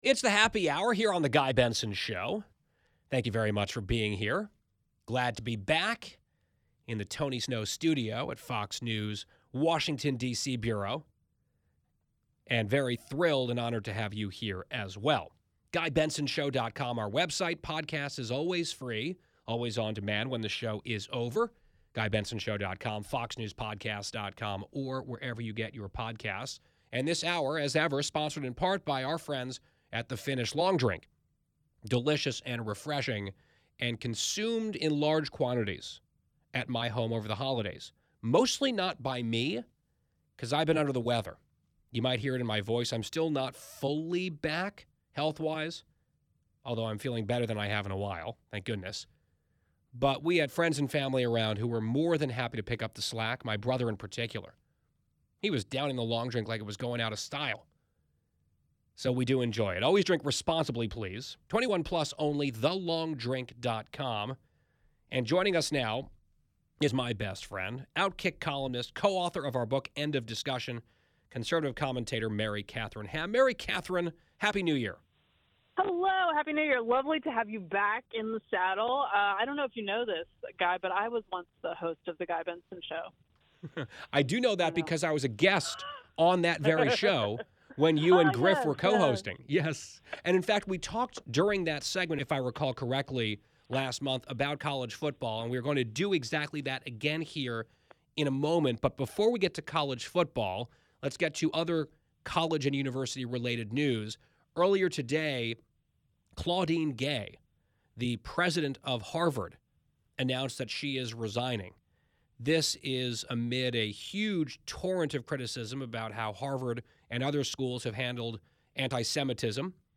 Benson and Ham also talk about the CFP and other college football updates, and they talk about who they are rooting for in the College Football Playoff Championship. Listen to the full interview below: